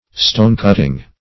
Stonecutting \Stone"cut`ting\, n.